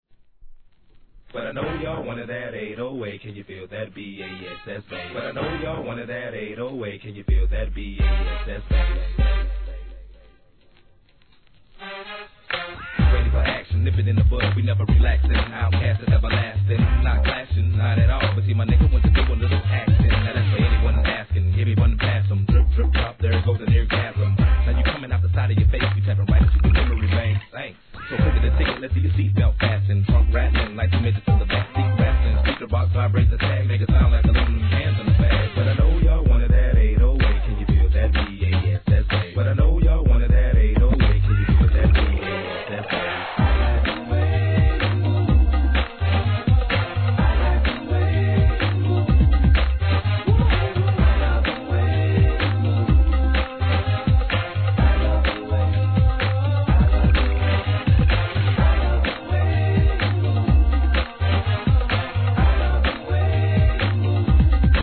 HIP HOP/R&B
早めのテンポにフックでは得意のソウルフルなコーラスを聴かせるチャート・ヒット!